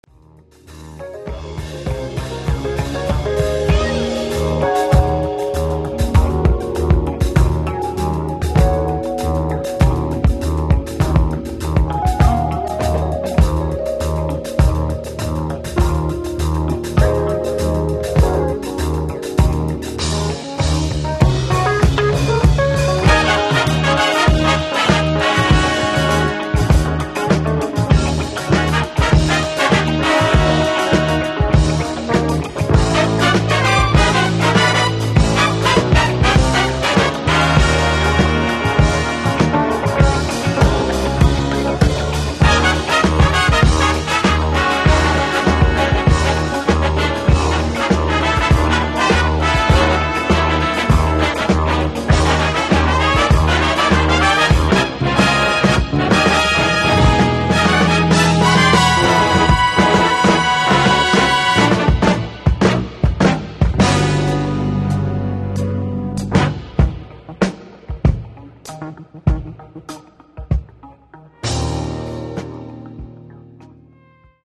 strings & woodwinds